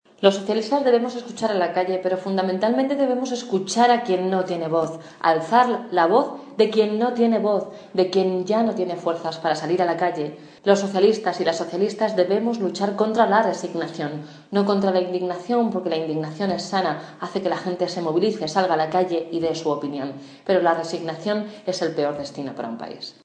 Acto público del PSOE en Almodóvar del Campo
Cortes de audio de la rueda de prensa